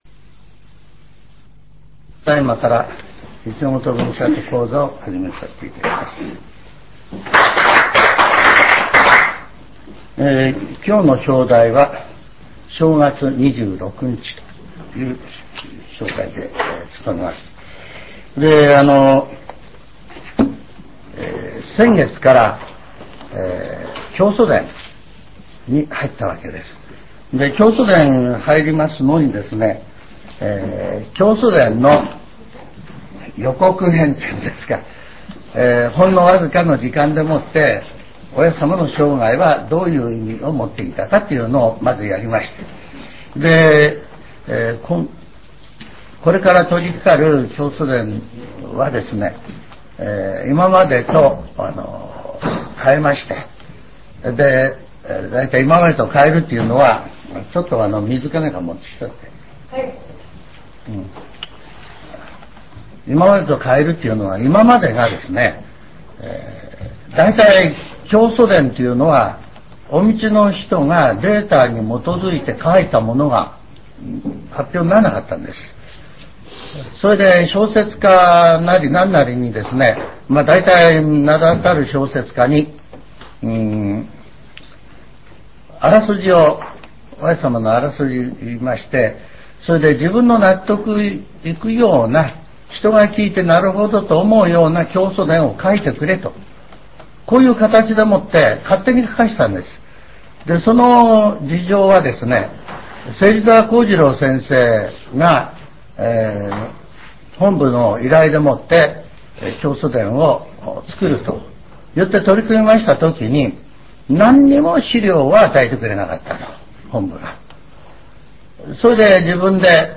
全70曲中8曲目 ジャンル: Speech